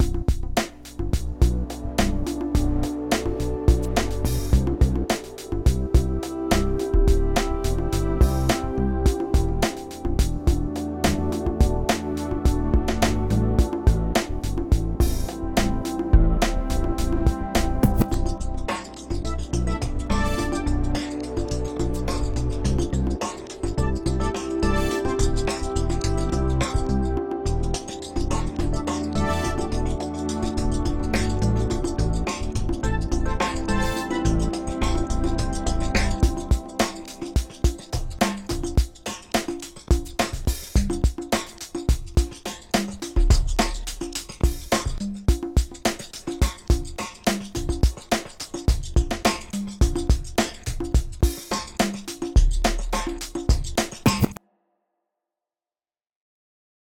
Time for another chill vibe called Evening Relaxation: